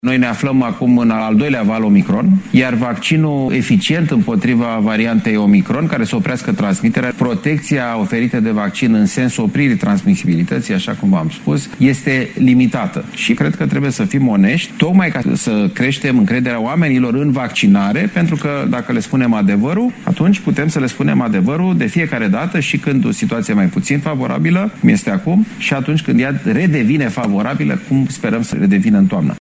Ministrul sănătăţii spune că actualul vaccin NU mai este eficient împotriva noilor tulpini Omicron: